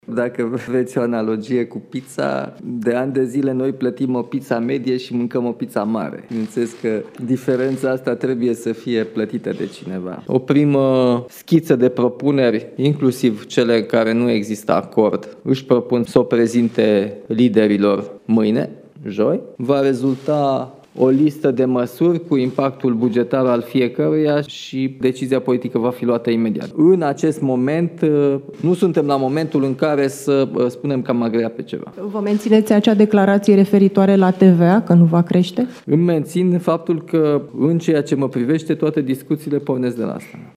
„Luni, vom avea o primă schiță a programului de guvernare”, spune președintele Nicușor Dan, în prima sa conferință de presă organizată la Cotroceni.